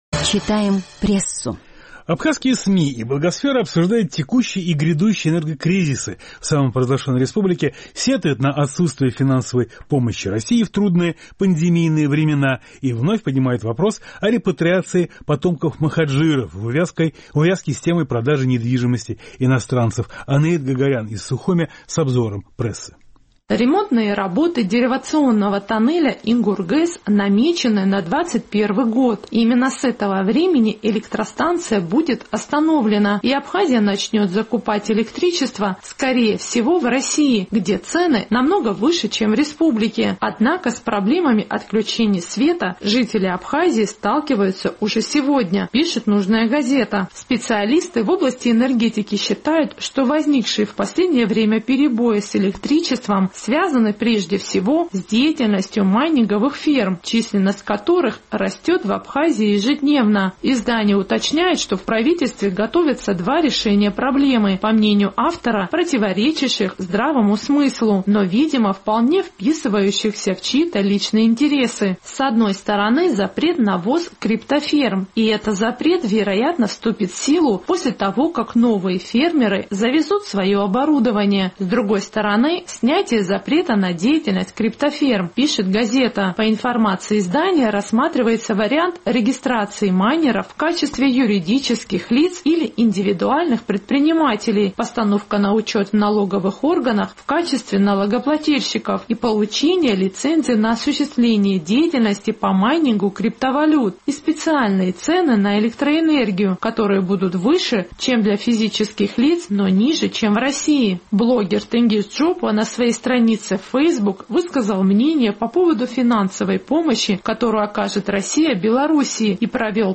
Обзор абхазской прессы